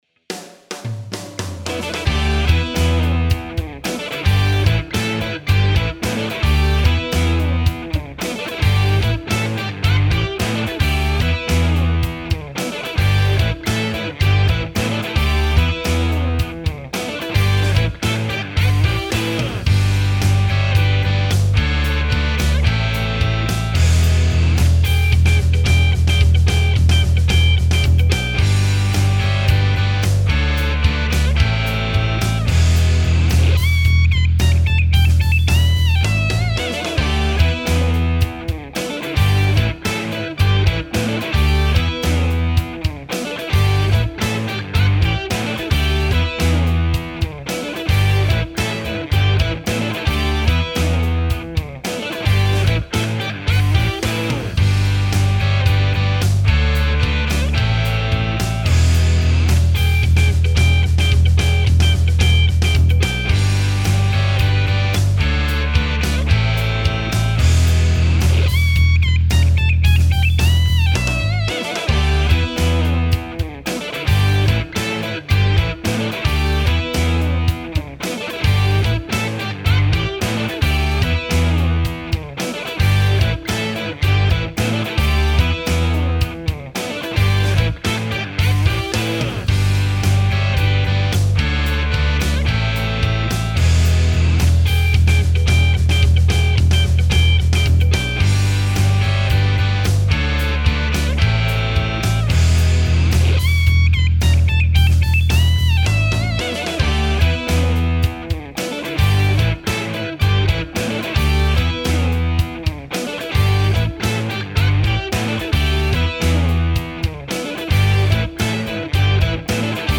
音楽ジャンル： ロック
楽曲の曲調： MIDIUM